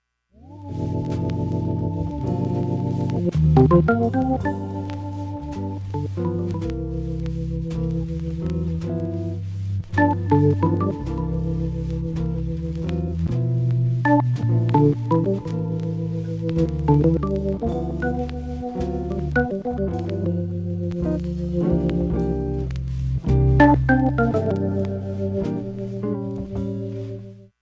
ファンキーで、ブルージーな名作